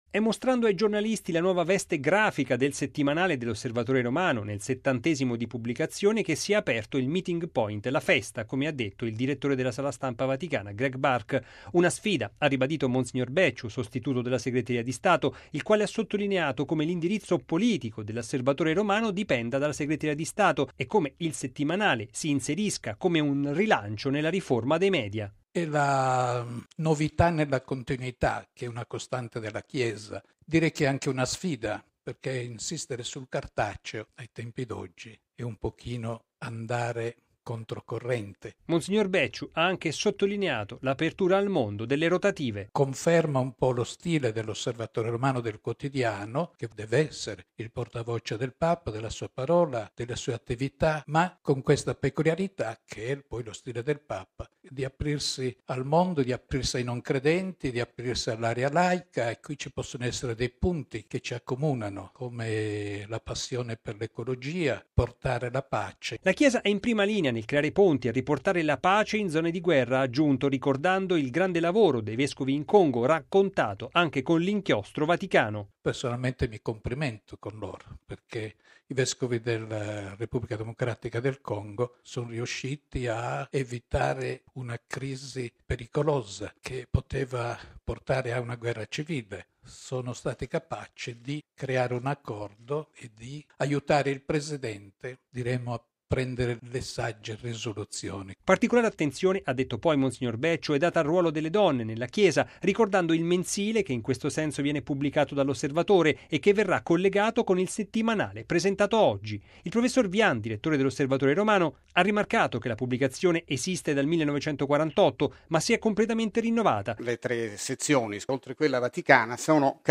Bollettino Radiogiornale del 10/01/2017